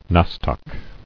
[nos·toc]